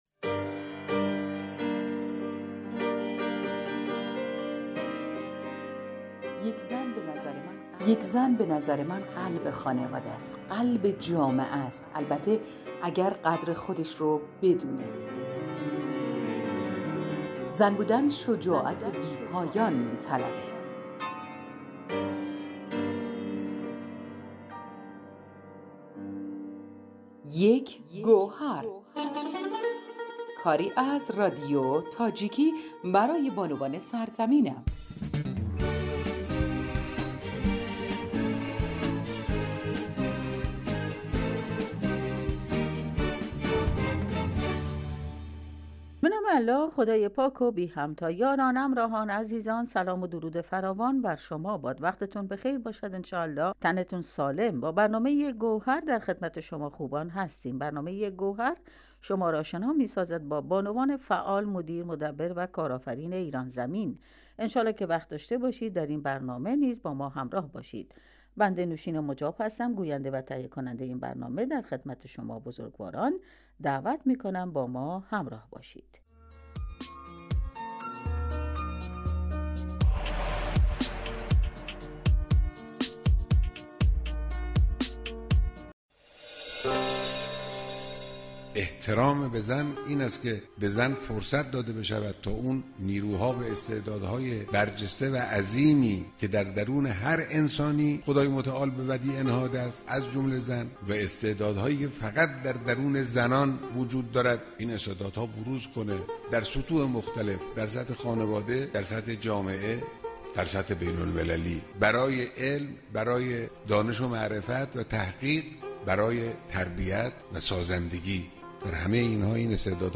Дар силсилабарномаҳои "Як гавҳар", ҳар ҳафта ба масоили марбут ба занони эронӣ пардохта мешавад ва роҳҳои корофаринӣ ва ҳунарҷӯиро ба занони тоҷик ва Осиёи Миёна муъаррифӣ мекунад. Ин барнома, панҷшанбеи ҳар ҳафта аз Садои Хуросон пахш мешавад.